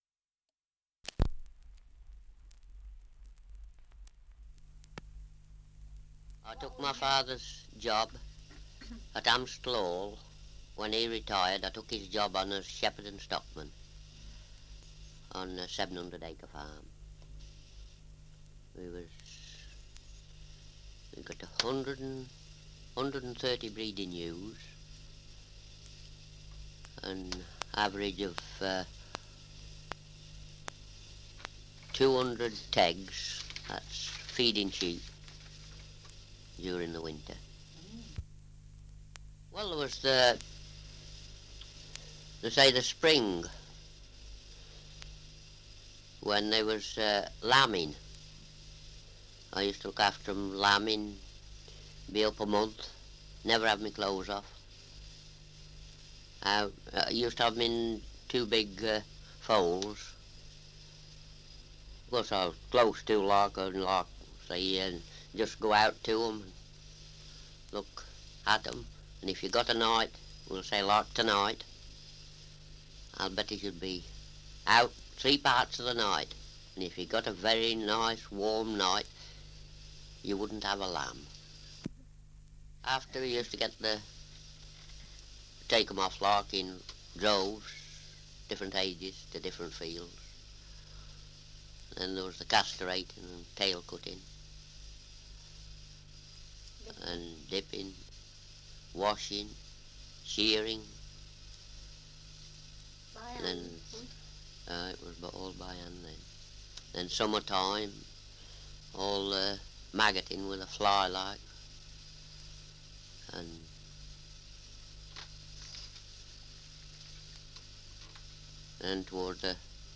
Survey of English Dialects recording in Mavesyn Ridware, Staffordshire
78 r.p.m., cellulose nitrate on aluminium